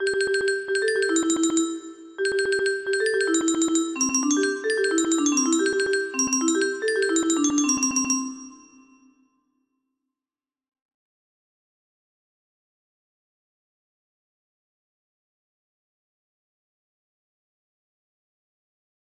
07Tue Tue paper music box melody